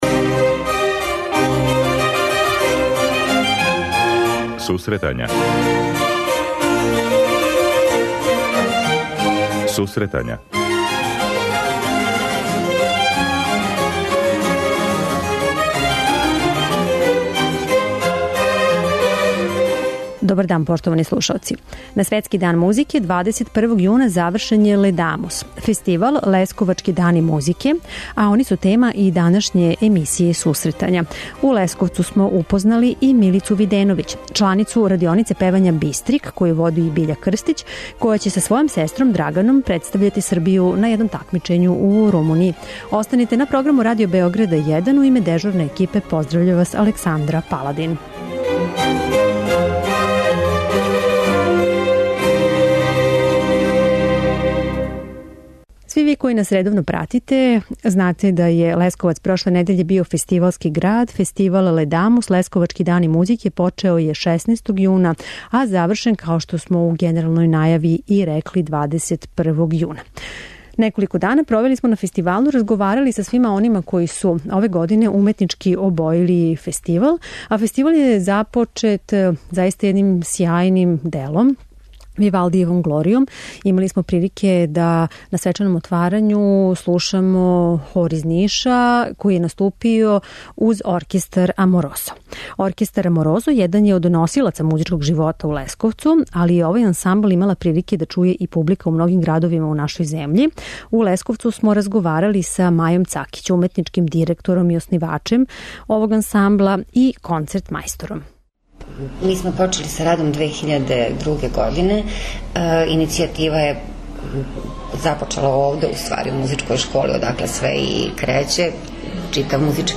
преузми : 26.19 MB Сусретања Autor: Музичка редакција Емисија за оне који воле уметничку музику.